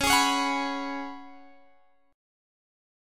Listen to C#7sus4 strummed